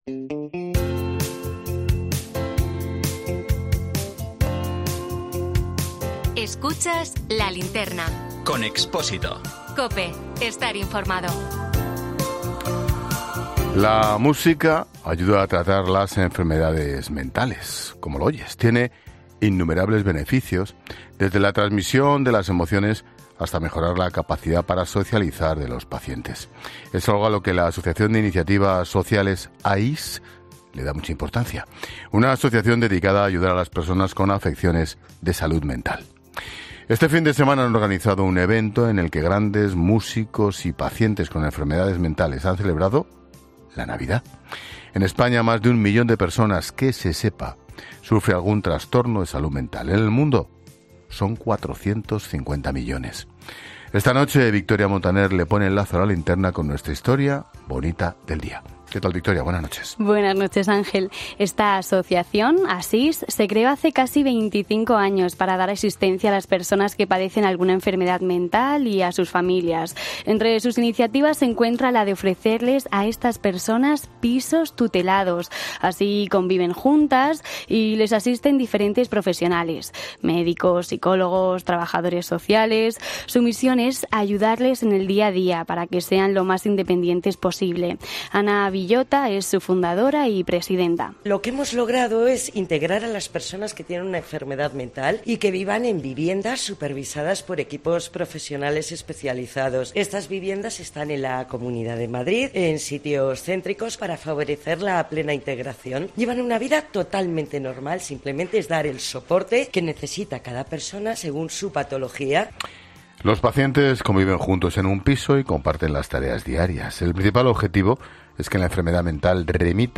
En 'La Linterna' acudimos a un evento organizado por AISS, una asociación que tutela viviendas a enfermos mentales
AISS, la Asociación de Iniciativas Sociales, ha organizado este fin de semana un evento emocionante, lleno de música, en el que artistas de nuestro país y pacientes con enfermedades mentales han celebrado juntos la Navidad.